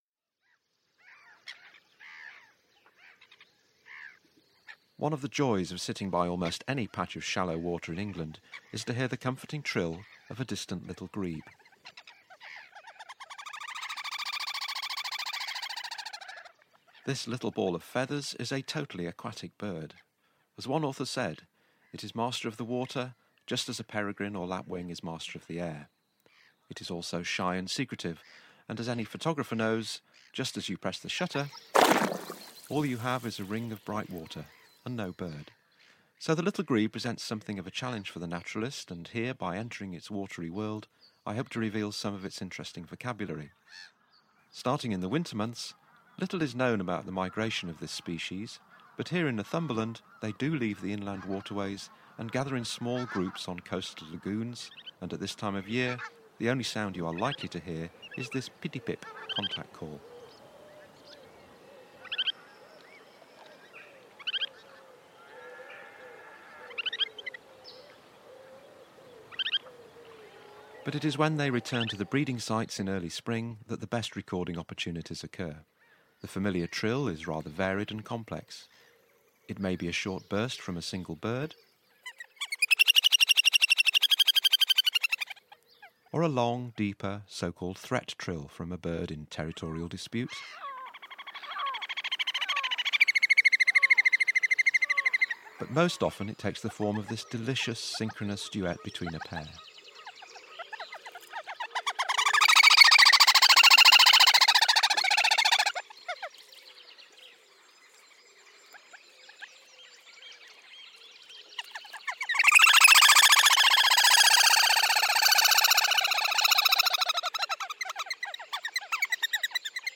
Little Grebes in close up
A short piece about Little Grebe vocabulary. Winner, Grand Prix Audio 2016, Jean Thevenot Medal, 65th International Amateur Recording Contest Recording of the Year 2016, British Amateur Audio Recording Contest Winner, 2010 Wildlife Sound Recording Society Documentary Competition.